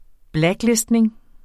Udtale [ ˈblagˌlesdneŋ ]